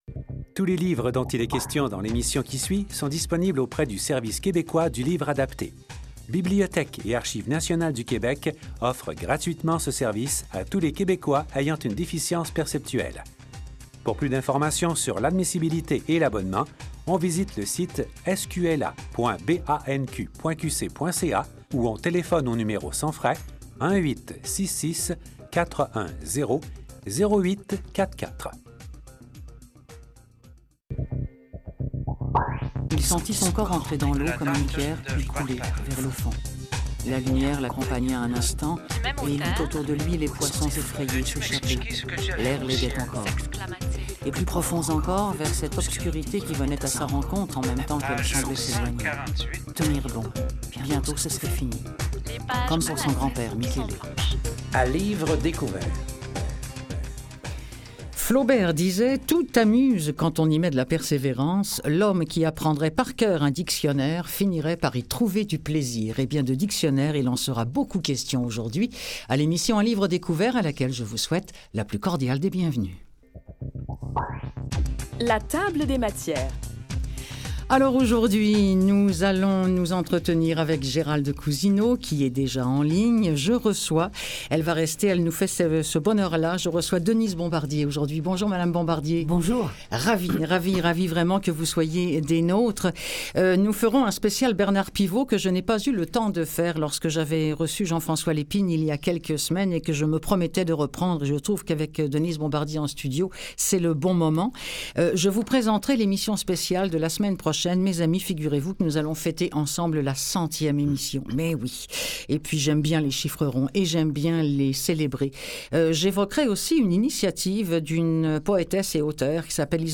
Rediffusion 20 mai 2015 - Quel héritage laissons-nous aux prochaines générations?